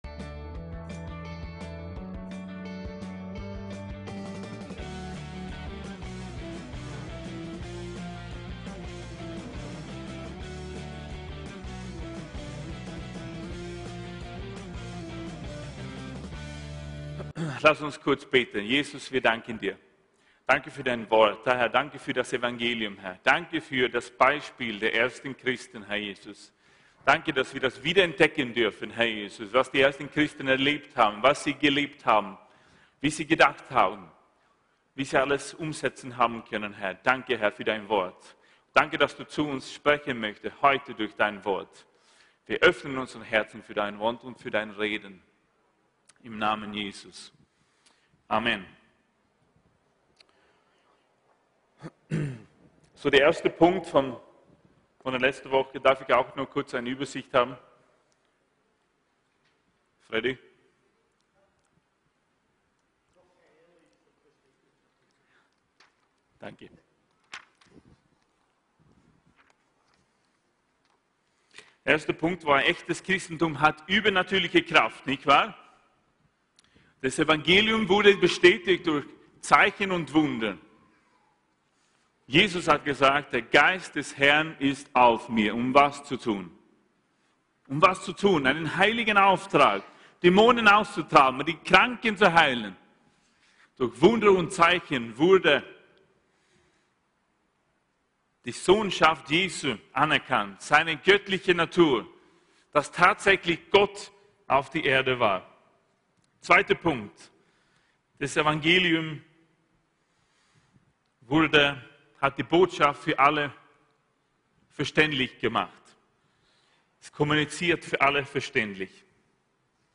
Gottesdienste